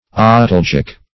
Search Result for " otalgic" : The Collaborative International Dictionary of English v.0.48: Otalgic \O*tal"gic\, a. (Med.)
otalgic.mp3